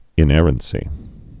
(ĭn-ĕrən-sē)